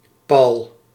Summary Description Nl-Paul.ogg Dutch pronunciation for "Paul" — male voice.